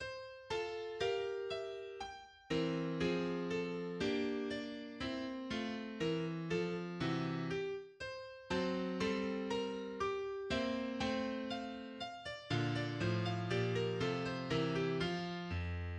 en fa majeur
Genre Sonate pour piano et violon
3. Andante à variations (6 variations), en fa majeur, à
Première reprise du Thème: